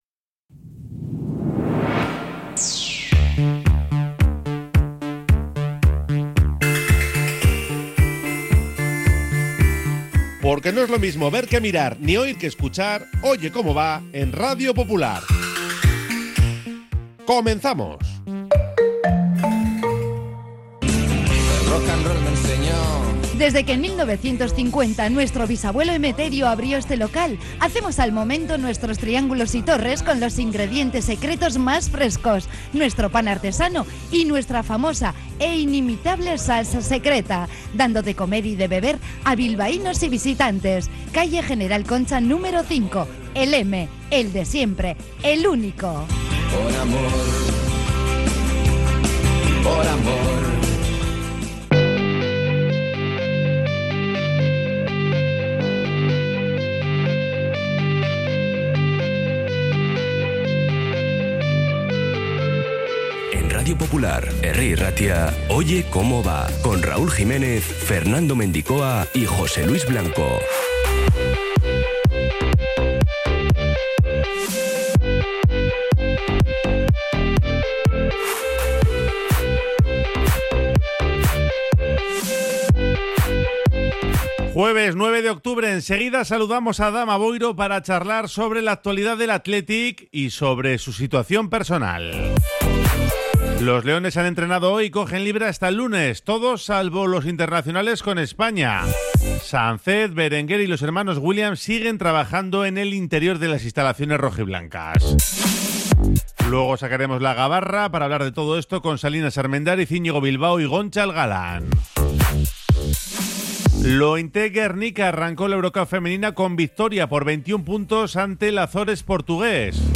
Oye Cómo Va 09-10-25 | Entrevista con Adama Boiro, Lointek Gernika y Jon Rahm